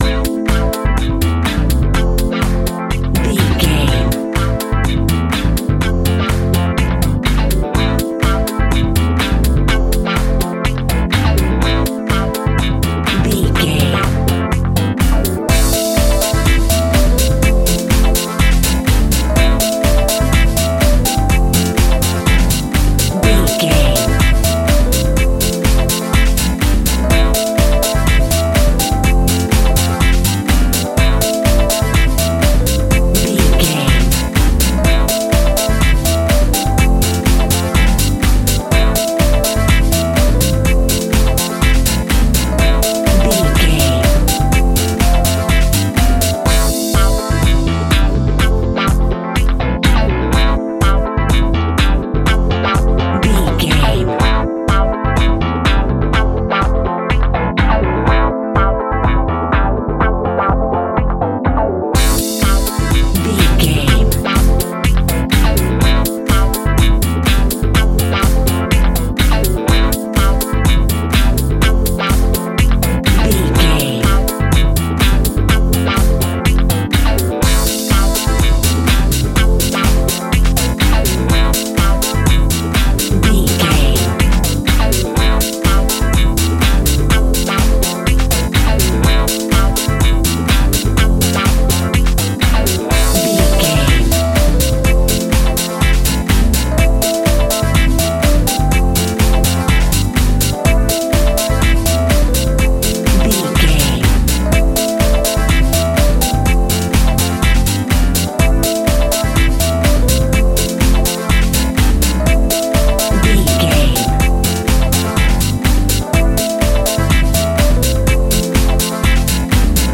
Aeolian/Minor
groovy
uplifting
driving
energetic
bass guitar
electric guitar
drum machine
synthesiser
electric piano
disco house
upbeat
clavinet
horns